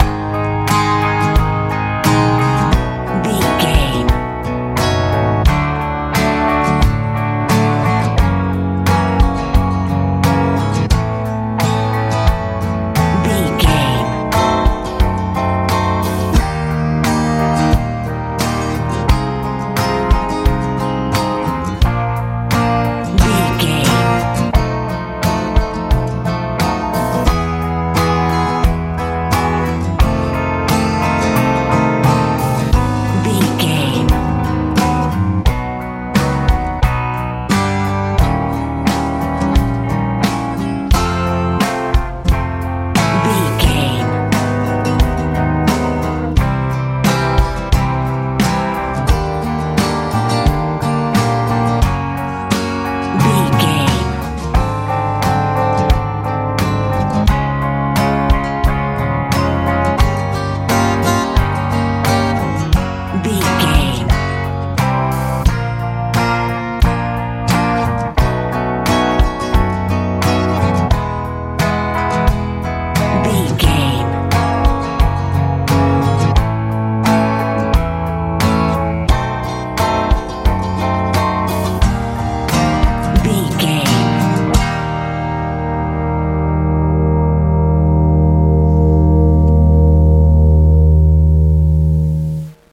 lite pop feel
Ionian/Major
A♭
cool
confident
acoustic guitar
bass guitar
drums
80s
90s